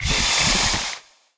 Index of /App/sound/monster/misterious_diseased_bow